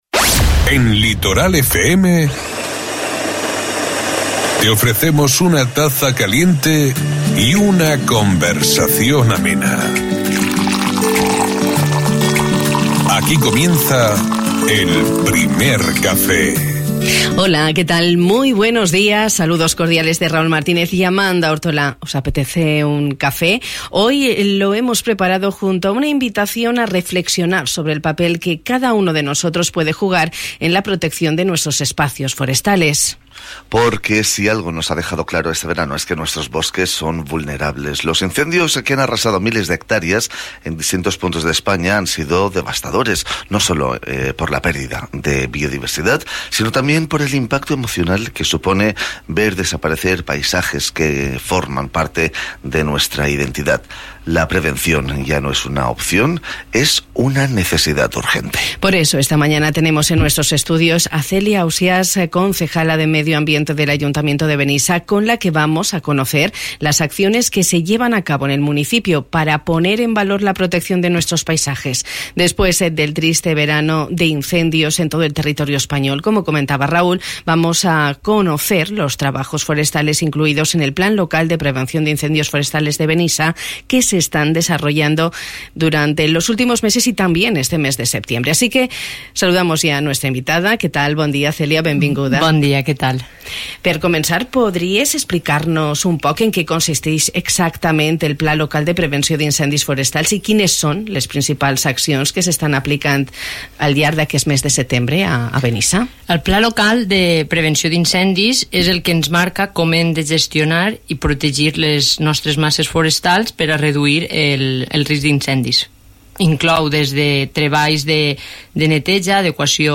Esta mañana hemos compartido tiempo de radio con Celia Ausias, concejala de Medio Ambiente del Ayuntamiento de Benissa.